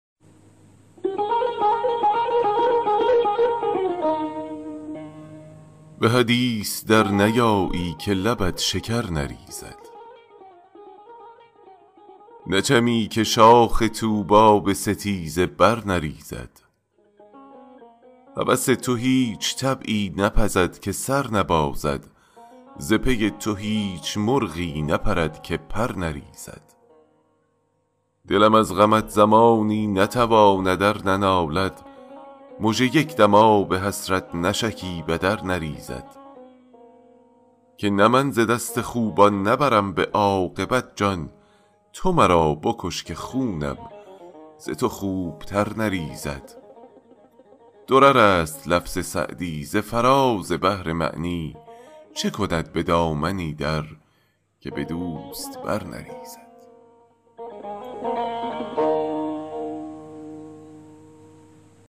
سعدی دیوان اشعار » غزلیات غزل ۱۸۸ به خوانش